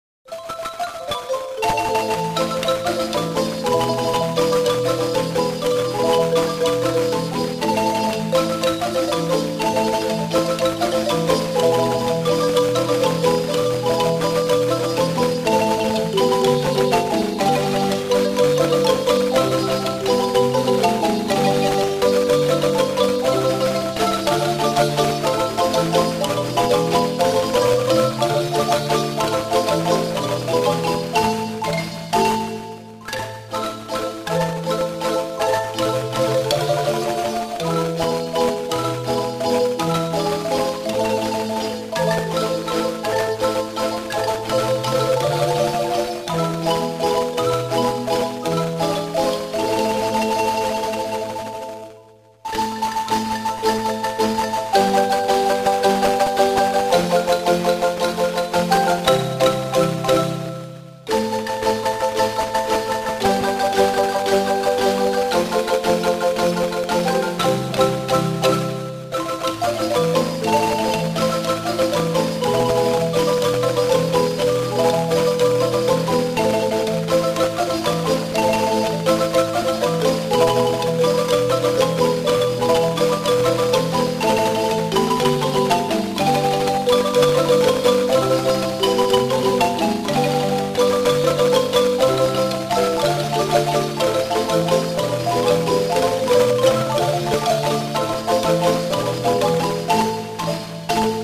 Música guanacasteca: marimba